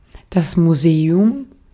Ääntäminen
IPA: /muˈzeːʊm/